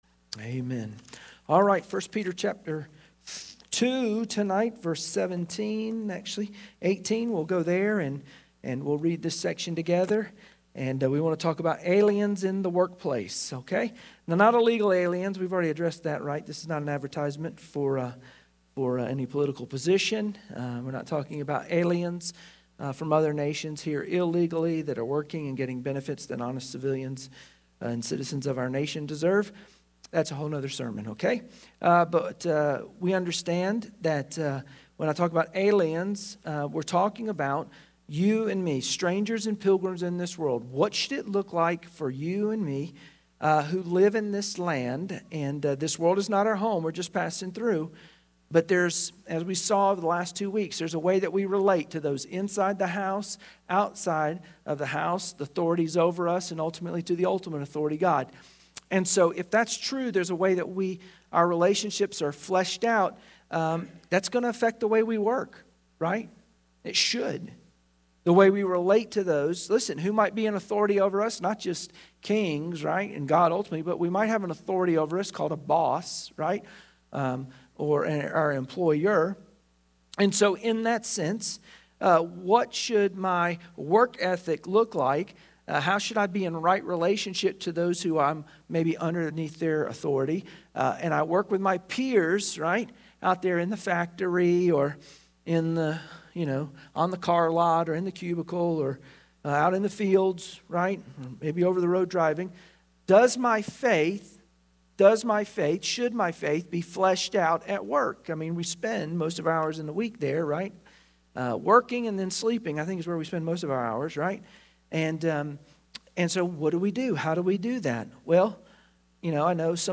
Wednesday Prayer Mtg Passage: 1 Peter 2:18-24 Service Type: Wednesday Prayer Meeting Share this